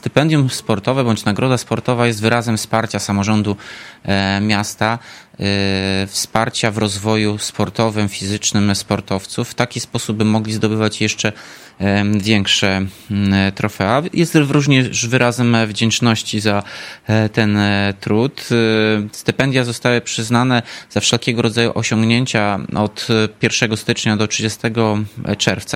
Mówi Tomasz Andrukiewicz, prezydent Ełku: